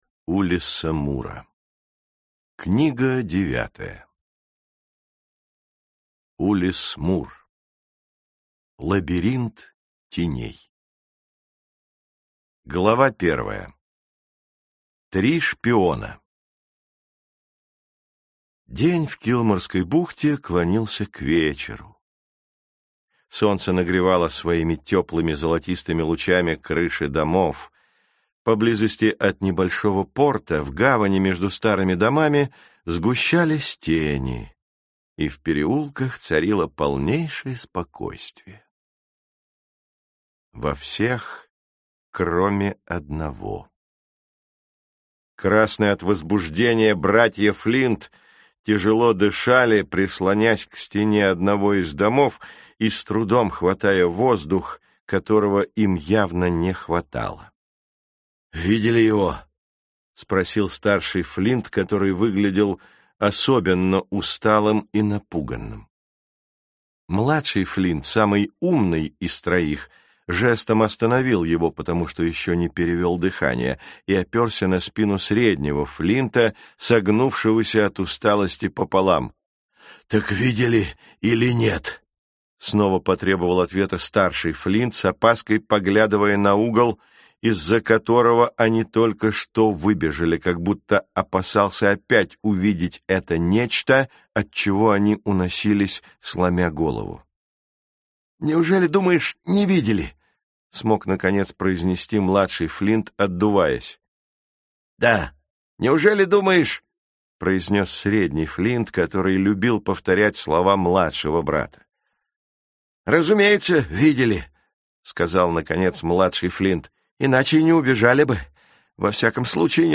Аудиокнига Лабиринт теней | Библиотека аудиокниг